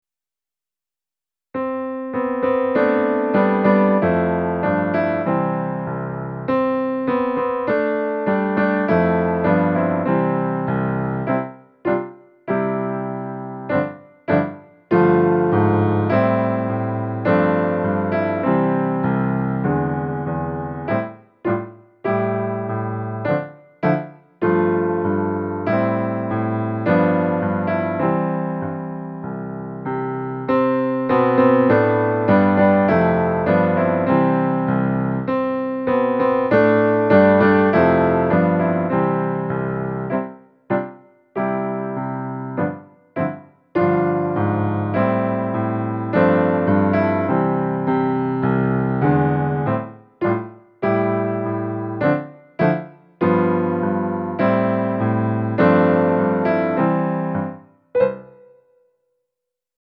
podkład